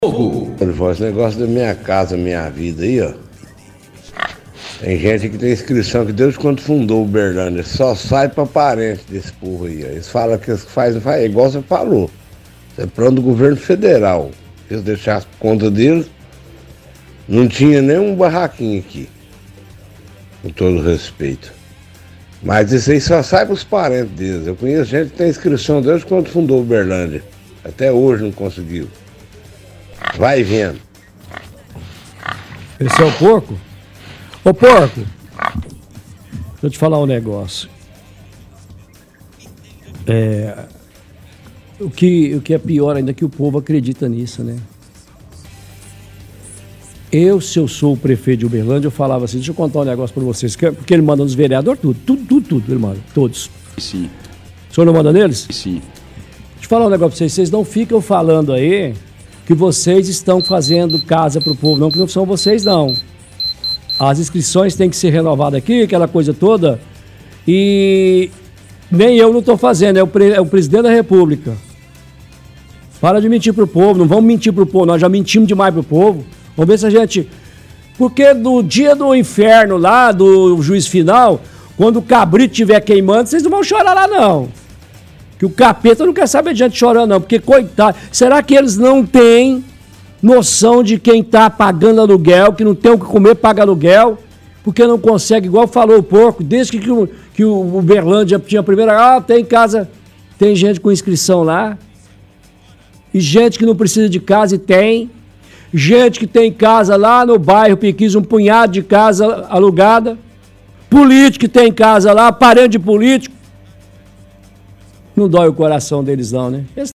– Ouvinte questiona que quem ganha o direito do programa Minha casa, Minha vida e só os parentes dos políticos, pois tem pessoas que fez cadastro desde quando a cidade fundou é até hoje não saiu casa.